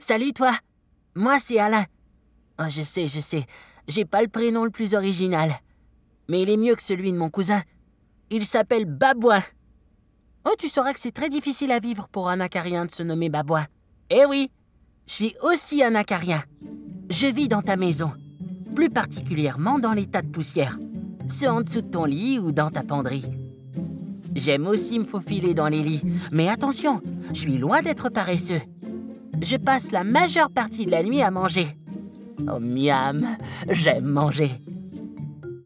voix de composition La vie secrète des insectes - Audiolivre